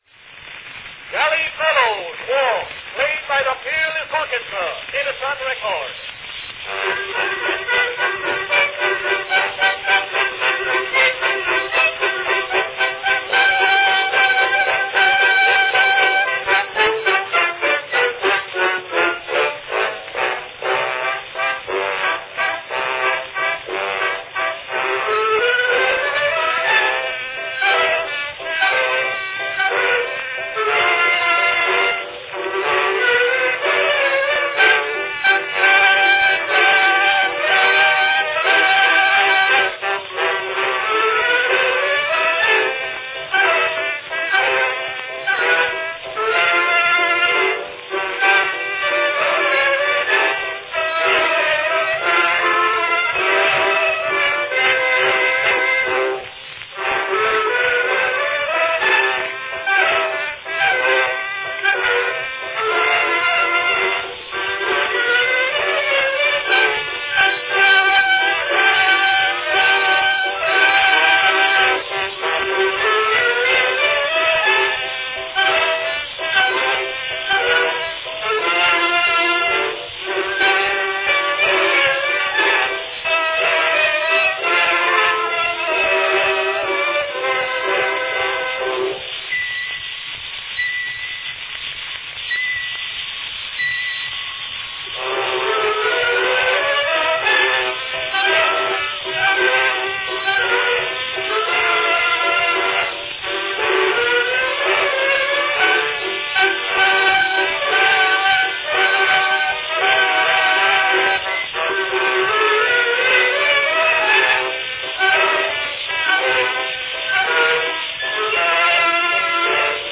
Enjoy a merry recording from 1899 by the Peerless Orchestra – the Jolly Fellows Waltz.
Cylinder # 7341 (5" diameter)
Category Orchestra
Performed by Peerless Orchestra
Announcement "Jolly Fellows Waltz, played by the Peerless Orchestra.  Edison record."
Here, it is heard on an Edison 5-inch diameter "concert" cylinder, suitable for playing at a large dance.